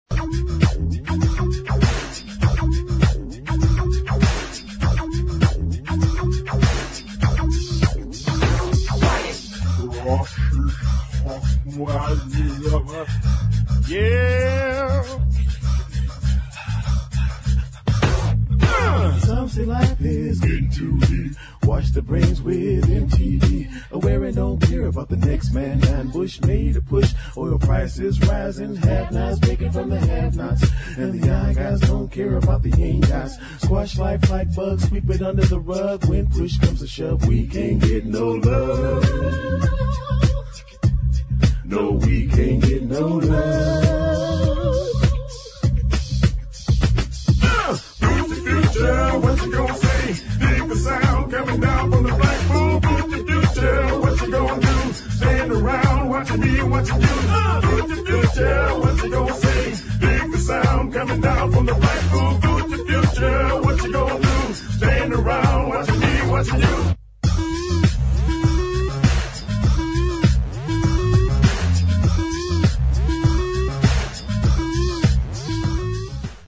ELECTRO DISCO ] ニューウェイヴ・テイストのエレクトロ・ディスコ！